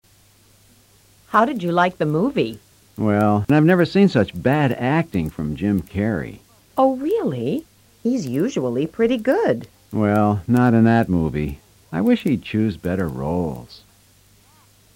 Cuatro personas opinan sobre libros y cine.